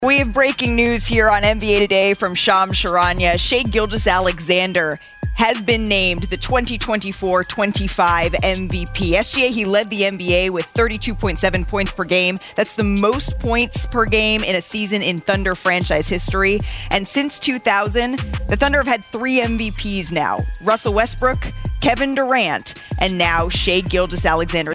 Here was the announcement Wednesday afternoon on ESPN.